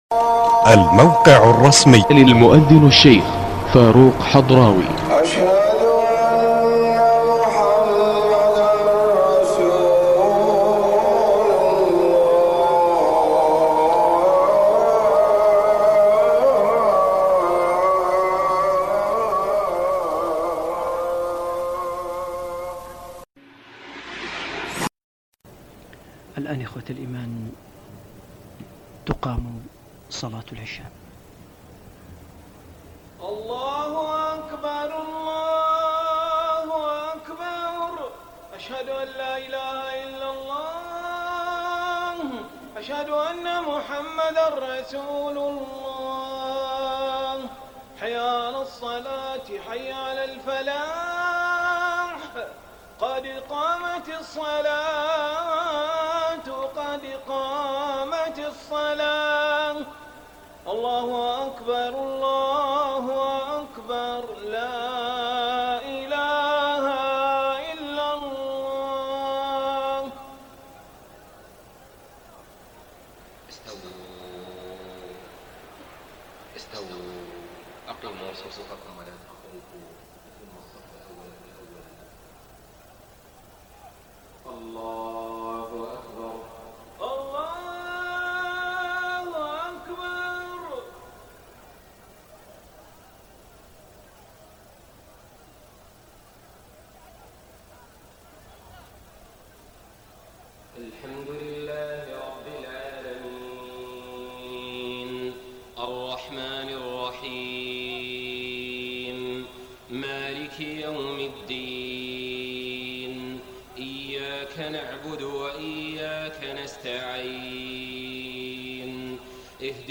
صلاة العشاء 25 رمضان 1429هـ من سورة الأعراف 54-58 > 1429 🕋 > الفروض - تلاوات الحرمين